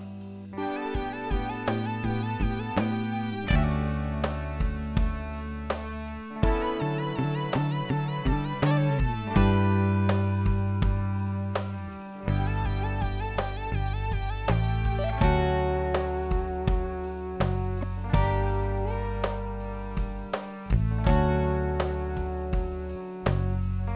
Minus Acoustic Guitars Pop (1970s) 5:18 Buy £1.50